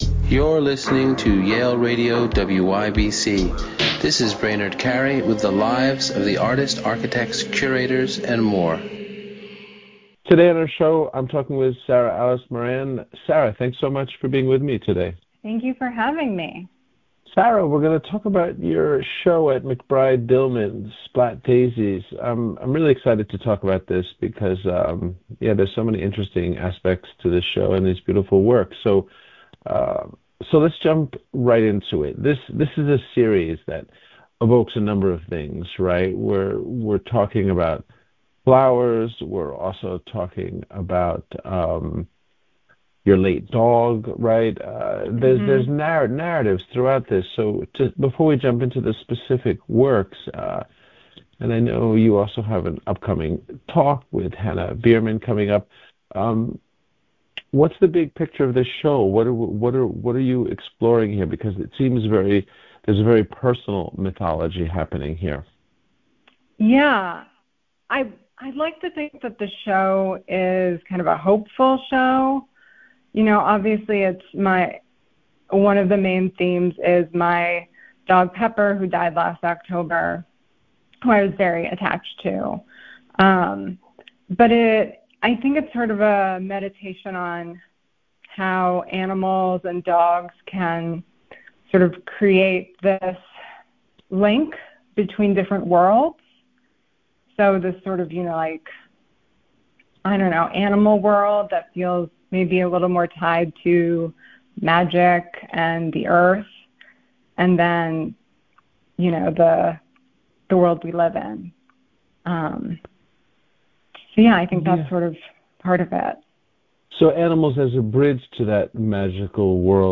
Interviews from Yale University Radio WYBCX